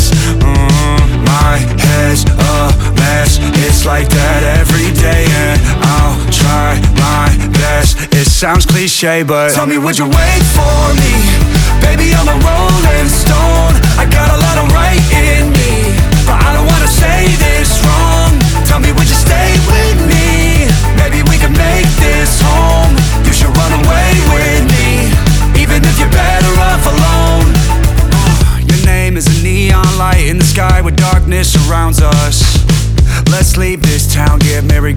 2025-05-23 Жанр: Альтернатива Длительность